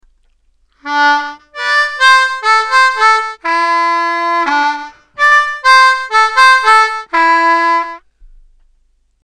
Funkin’ it up on the blues harmonica
It goes like this, starting with an octave jump: